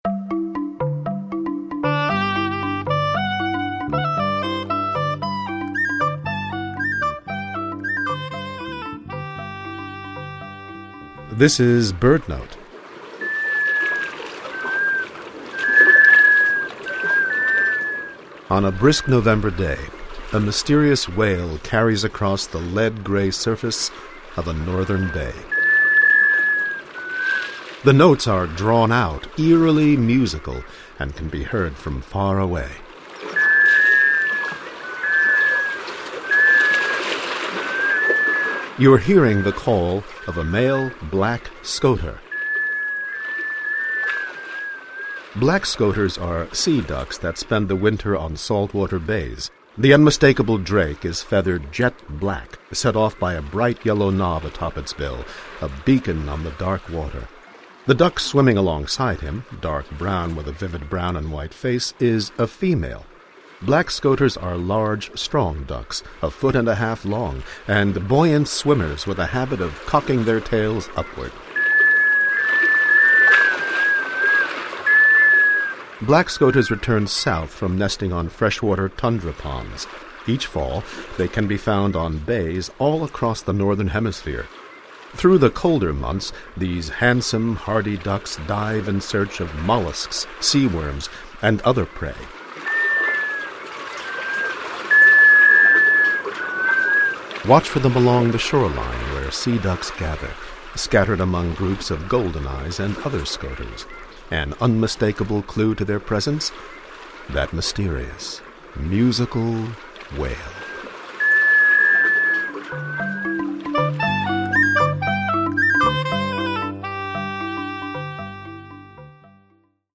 Black Scoter MP3
Male
Female